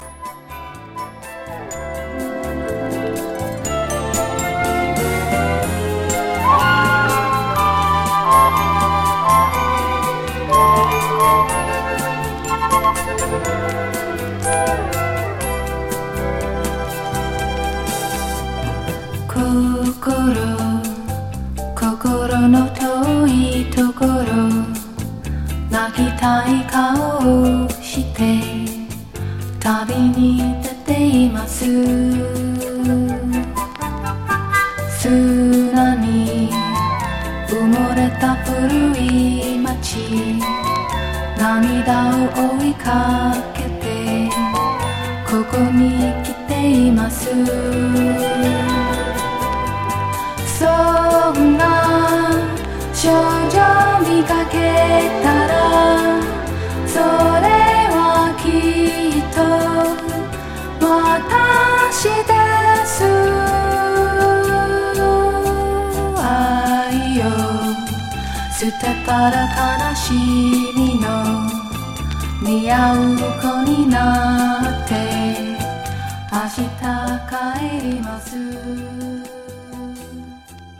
疑似和モノ・ソフトロック日本語歌謡♪